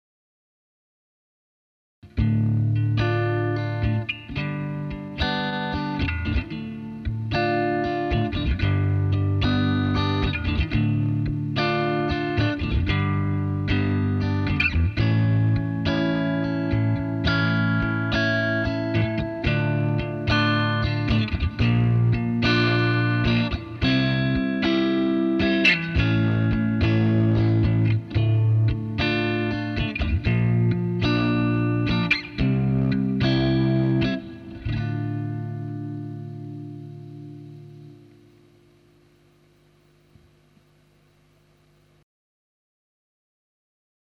Holy screeching brightness batman! That's pretty ear piercingly brittle.
I'm an analog and tape fan, but you're getting some heavy signal distortion in the low freqs.